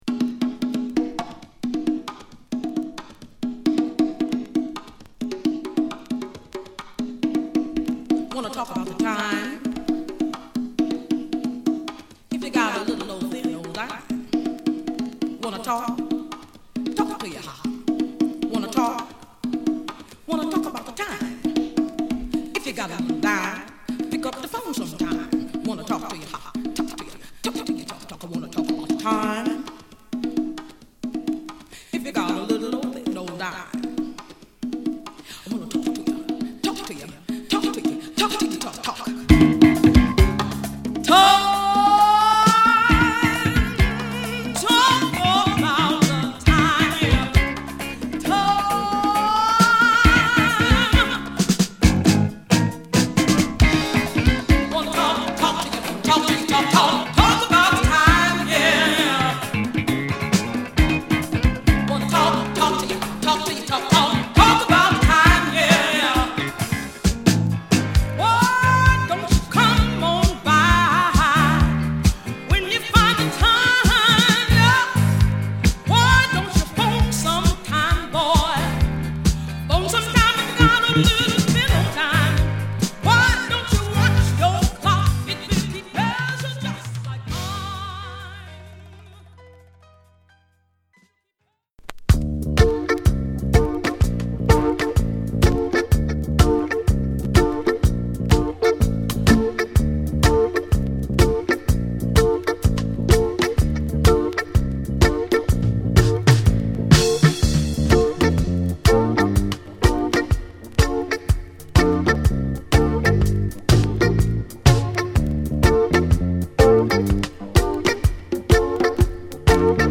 フランス産レアソウル！！